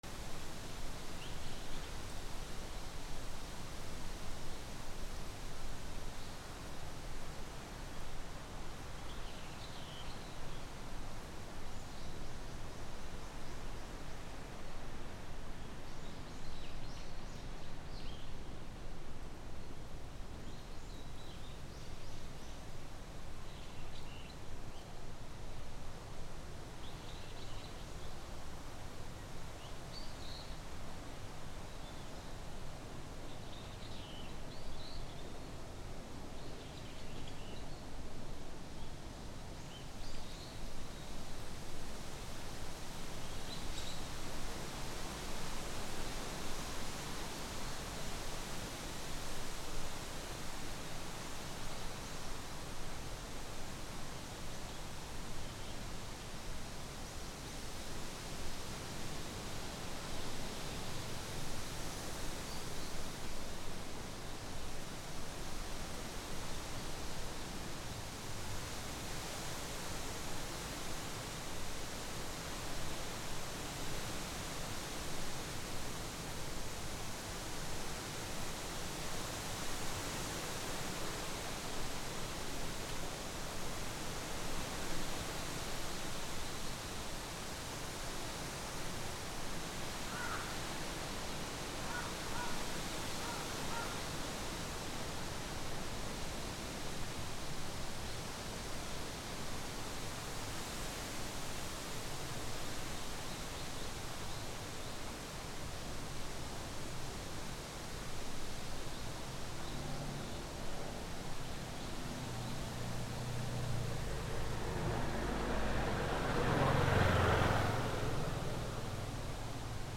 台風 木の葉の揺れる音
/ A｜環境音(天候) / A-45 ｜台風 嵐